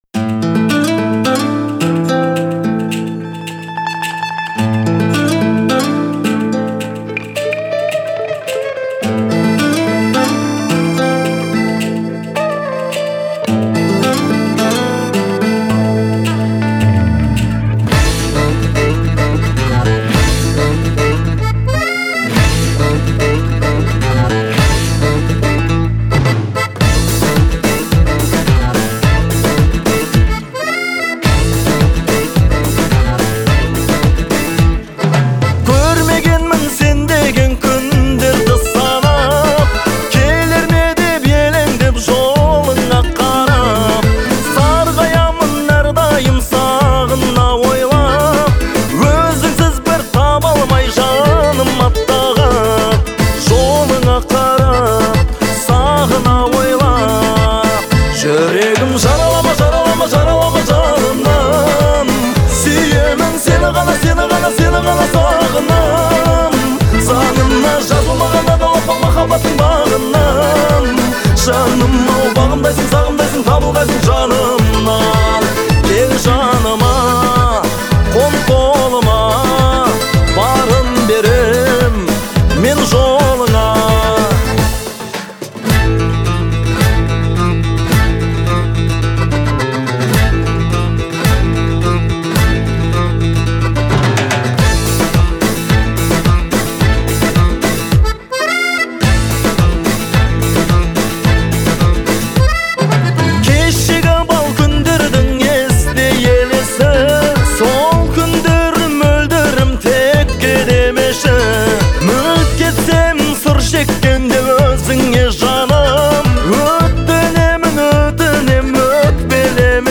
это проникновенная песня в жанре казахской народной музыки